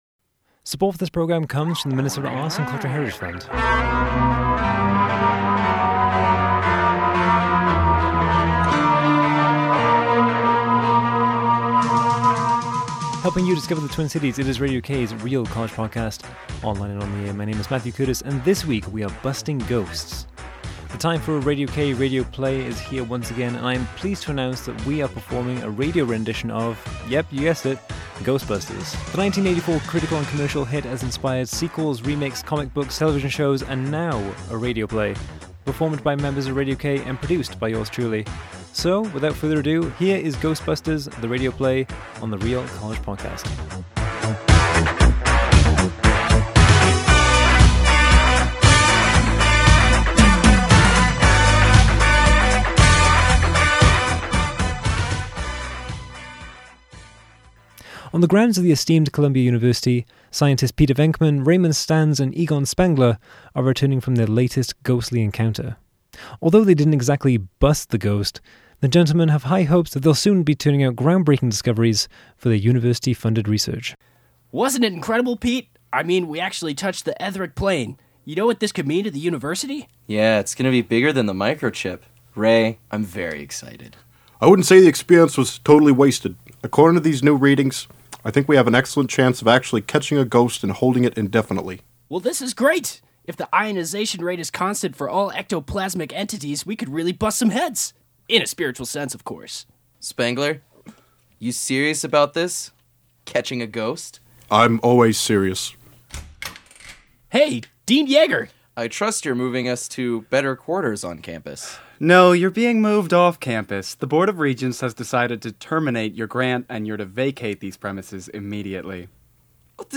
This week, we're busting ghosts. The time for a Radio K Radio Play is here once again and I'm pleased to announce that we are performing a radio rendition of - yep, you guessed it, Ghostbusters.